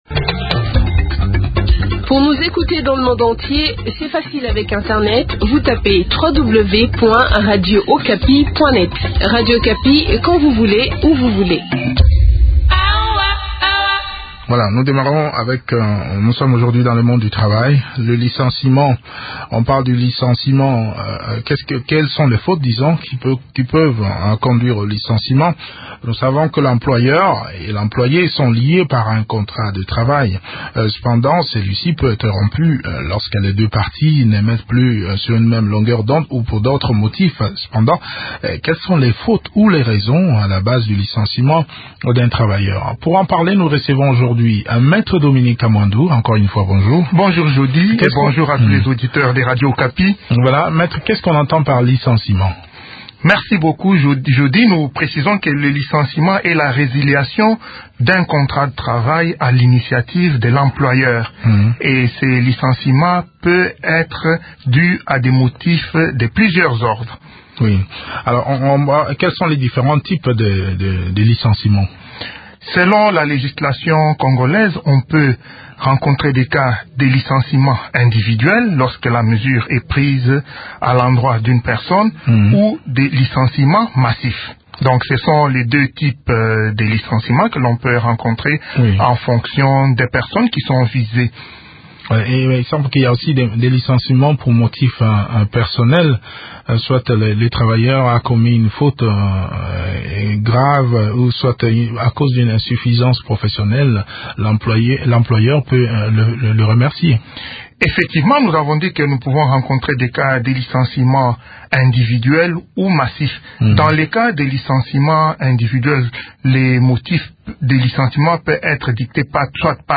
Quelles sont les fautes professionnelles pouvant conduire à la résiliation ? Eléments de réponse dans cet entretien